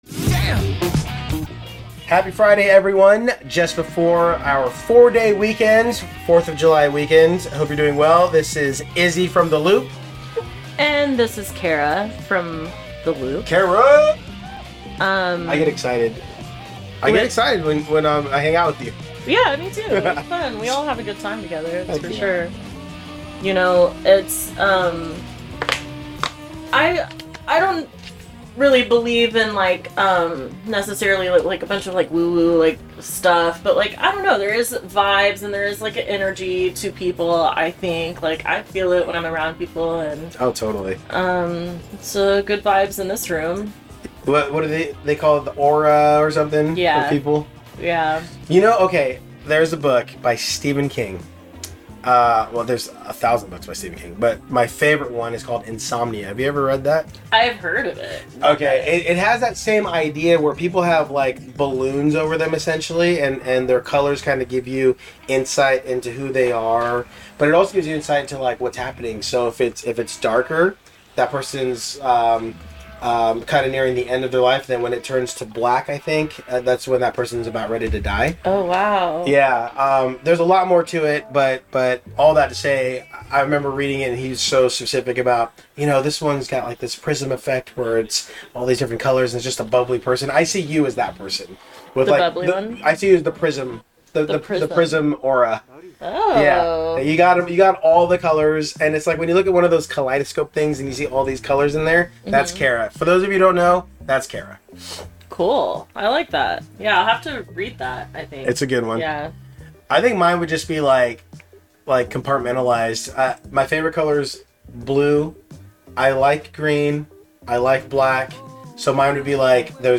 This episode aired live on CityHeART Radio on Friday June 30, 2023 at lunch time. This week on The Loop, they talked all things fireworks and 4th of July and had a mini-dialogue about professionalism vs laziness.